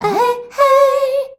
AHEHEY  A.wav